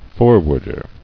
[for·ward·er]